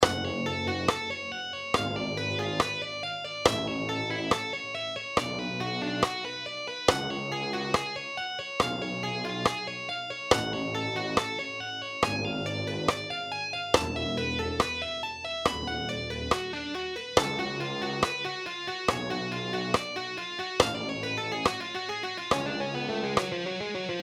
Remember its on D# Tuning
Here is the lick slowed down:
lessons-scales-harmonic_minor_arpeggios.mp3